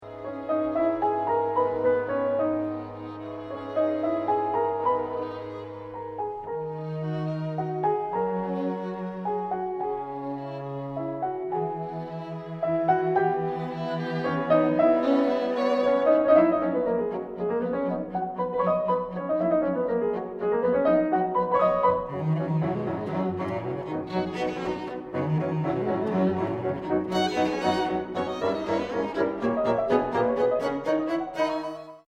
• Studio: Église Saint-Joachim (Pointe-Claire)